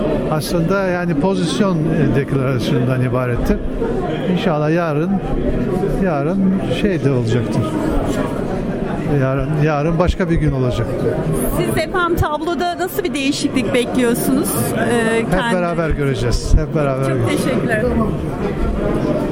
Ekmeleddin İhsanoğlu'yla söyleşi